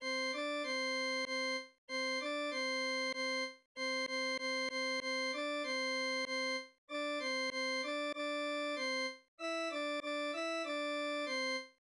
b) Bartók № 49b: mi-re-Do magú esőima